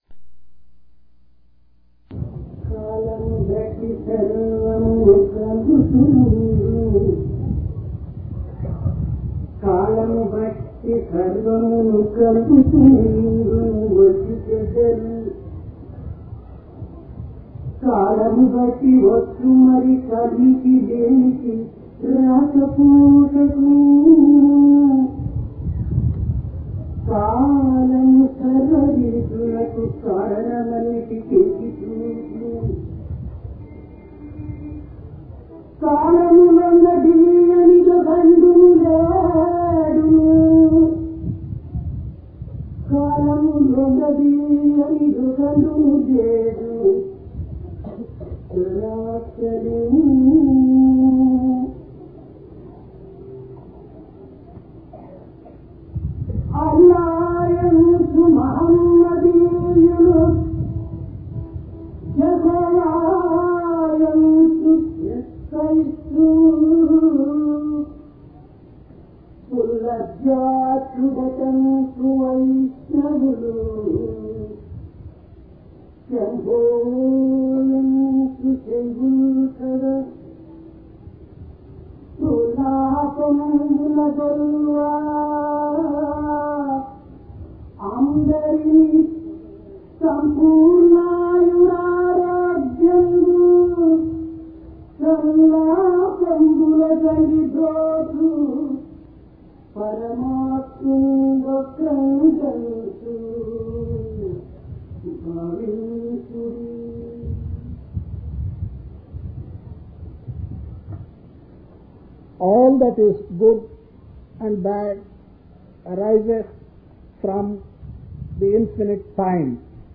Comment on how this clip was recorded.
Place Prasanthi Nilayam Occasion Shivarathri